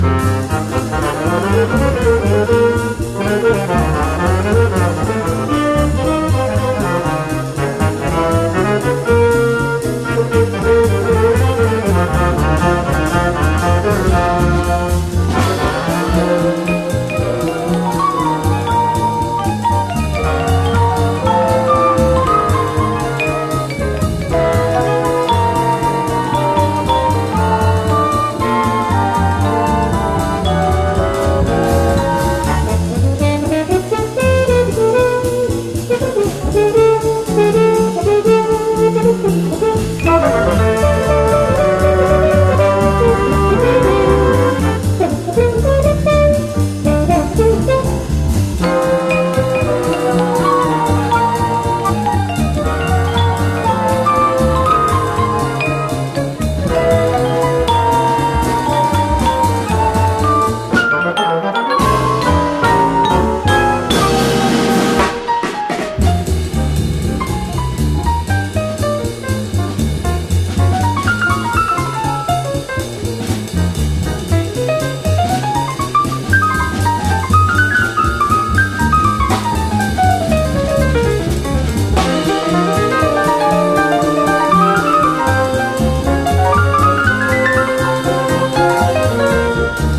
JAZZ / DANCEFLOOR / MODAL
オーボエやフレンチ・ホルンなども交えたヨーロピアンで優雅なアンサンブルに酔いしれます！